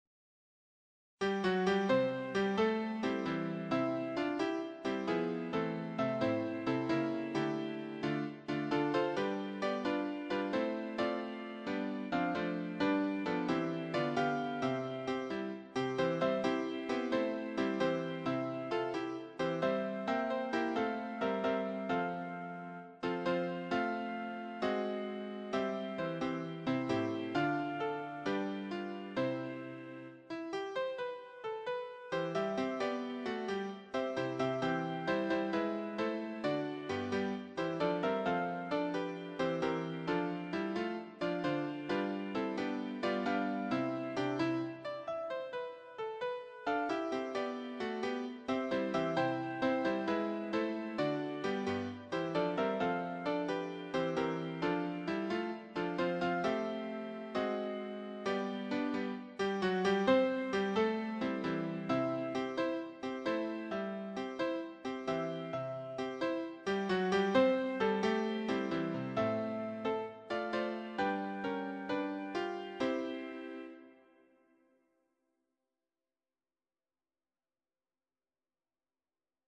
choir SATB
Sacred choral songs